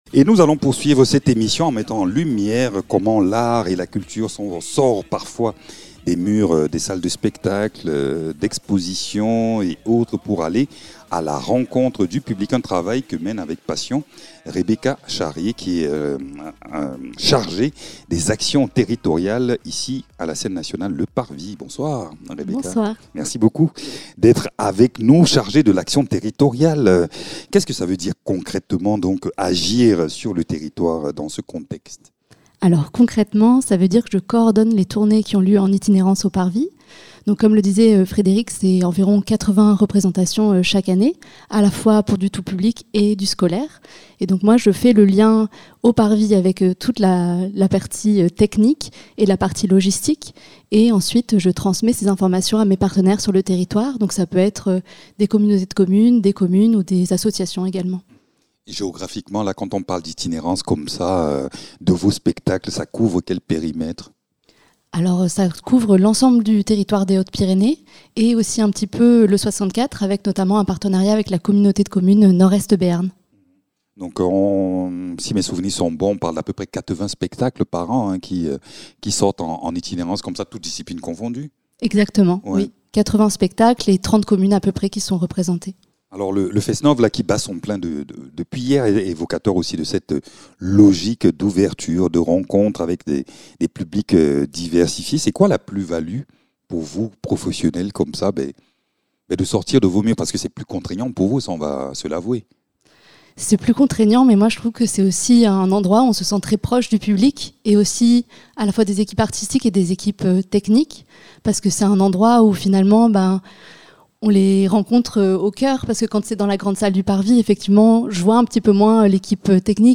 Dans cet entretien, elle revient sur les enjeux, les partenariats et les ambitions d’une action culturelle qui fait vibrer le territoire.